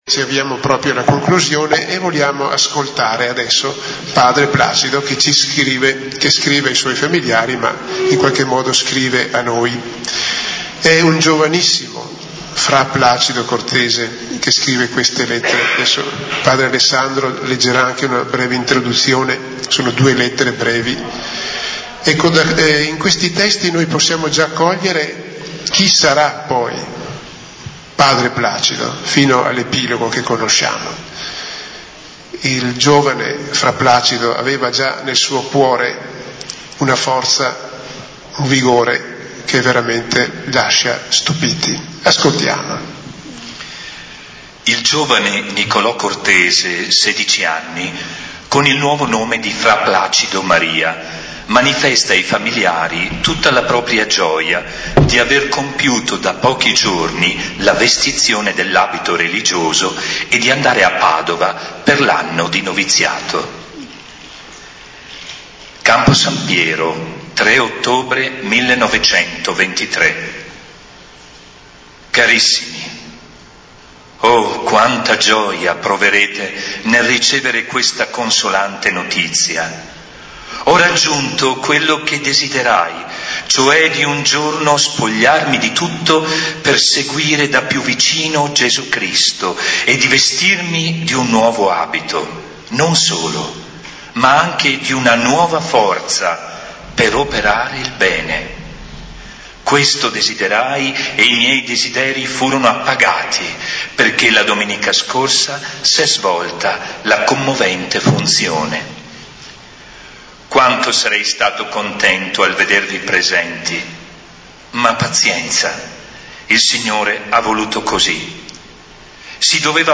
25 aprile 2019 - Convegno